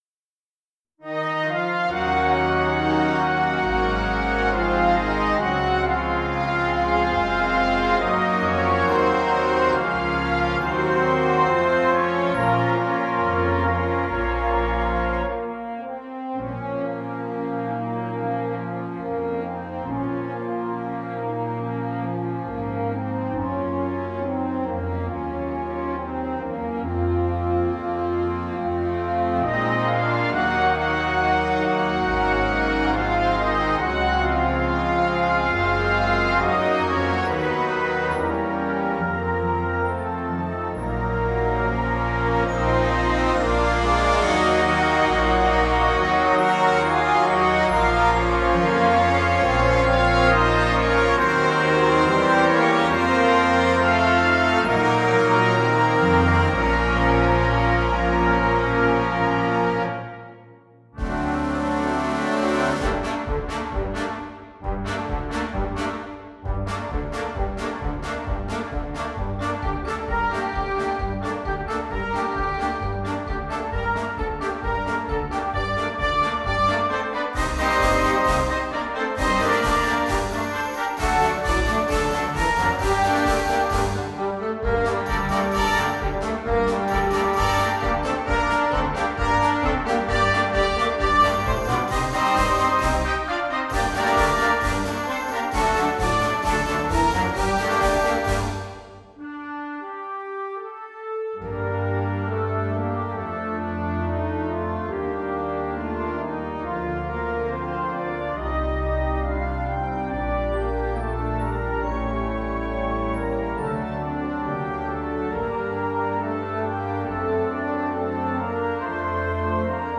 Gattung: Moderner Einzeltitel für Blasorchester
Besetzung: Blasorchester